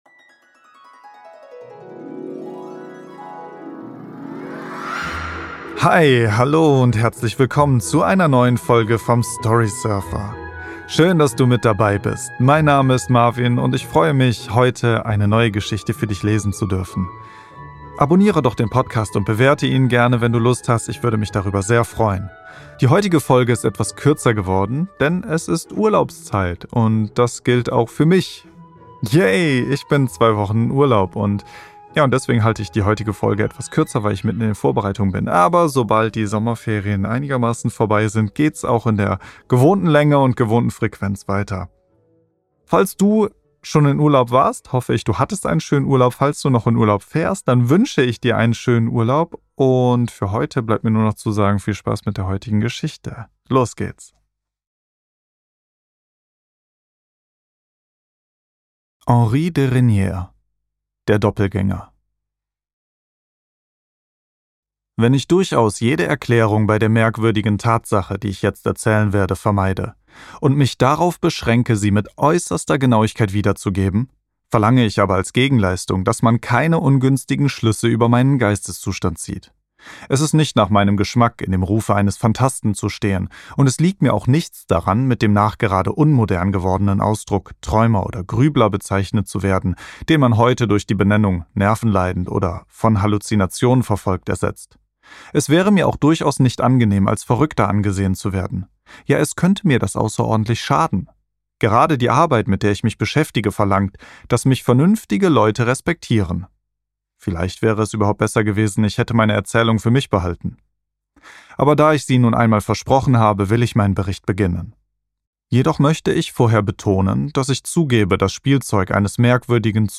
In dieser Storysurfer Podcast Folge lese ich "Der Doppelgänger" von Henri de Régnier über einen französischen Spaziergänger, der nach einem Besuch in Versailles eine mysteriöse Begegnung macht.